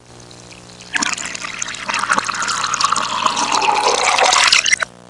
Down The Drain Sound Effect
Download a high-quality down the drain sound effect.
down-the-drain.mp3